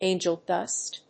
アクセントángel dùst